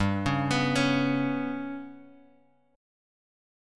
GMb5 chord {3 4 x 4 2 x} chord